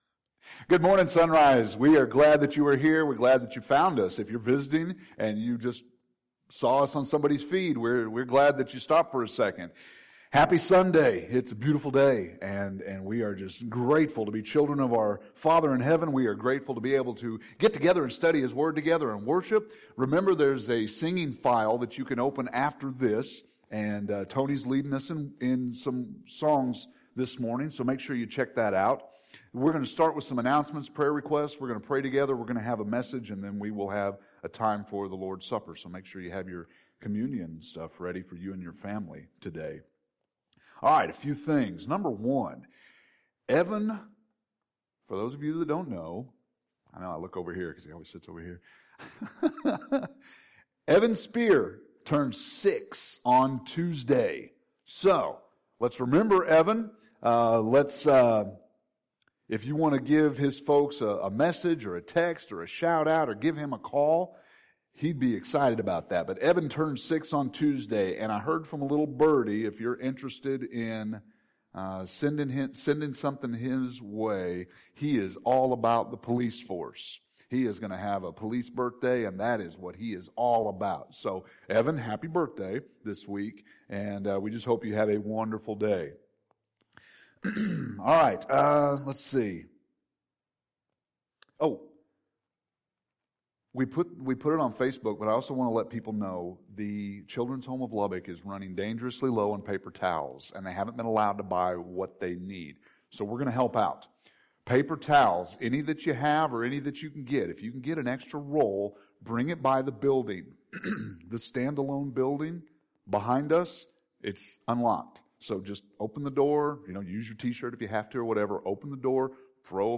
Sermon – Page 30 – Sermons